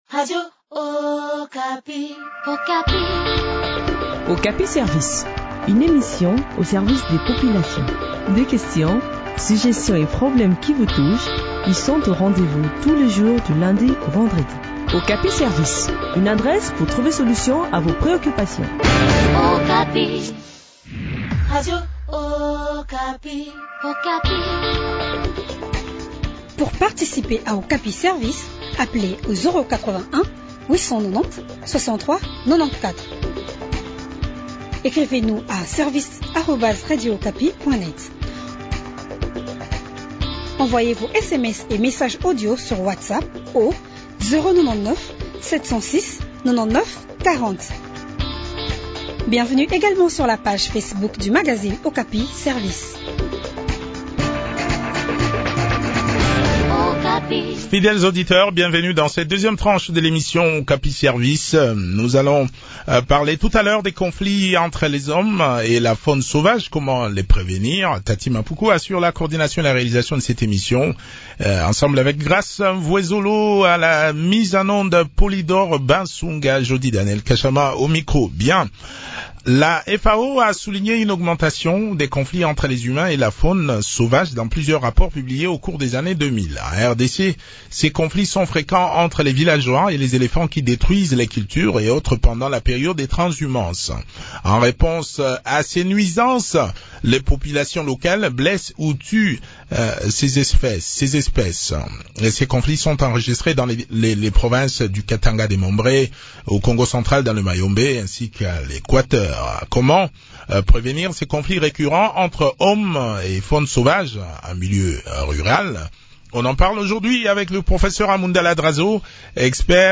a également participé à cet entretien.